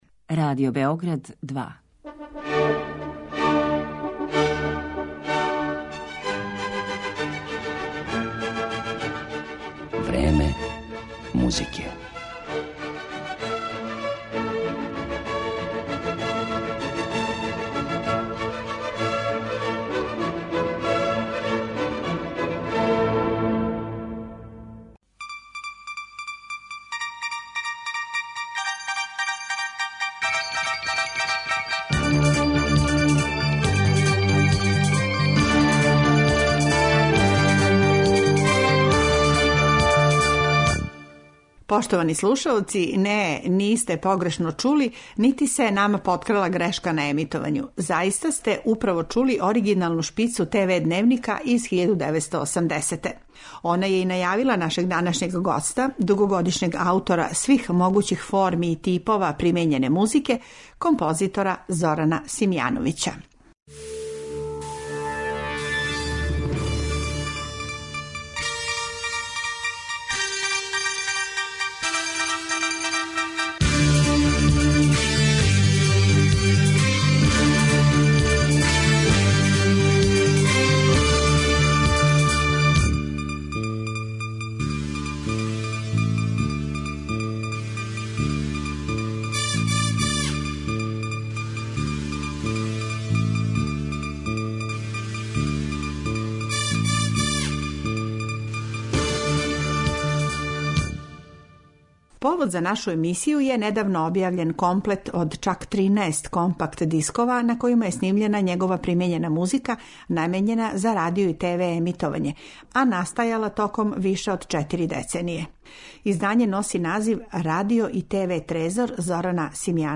Гост емисије је композитор ЗОРАН СИМЈАНОВИЋ, аутор који се искључиво определио за примењену музику и у њеним жанровима остварио богату каријеру овенчану многим наградама.